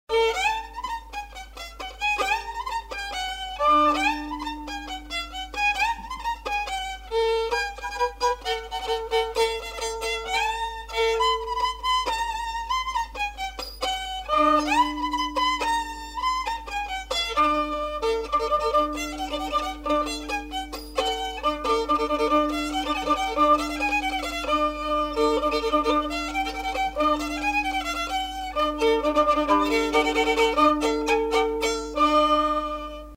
Aire culturelle : Lomagne
Lieu : Garganvillar
Genre : morceau instrumental
Descripteurs : rondeau
Instrument de musique : violon